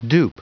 Prononciation du mot dupe en anglais (fichier audio)
Prononciation du mot : dupe